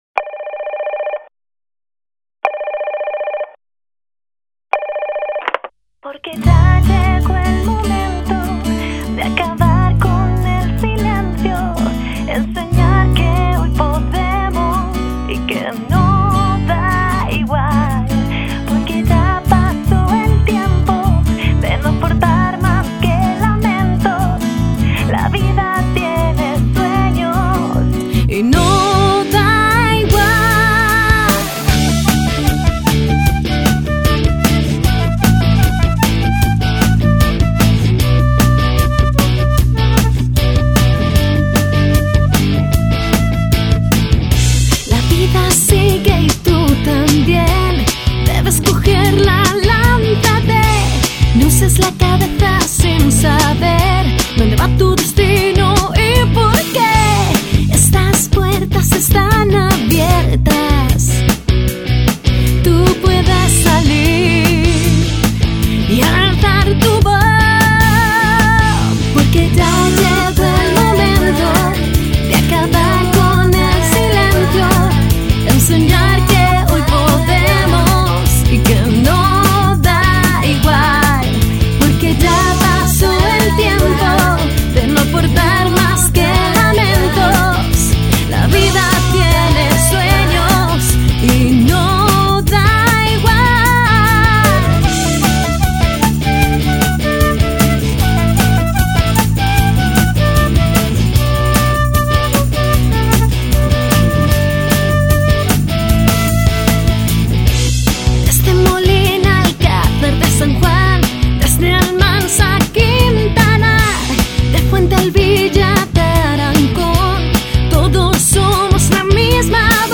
El punto final de esta presentación lo puso el grupo azudense “El secreto de Karnak’ que interpretó el tema ‘No es igual’ con el que arrancaron las palmas y los aplausos de los asistentes a la plaza de la Constitución de Azuqueca.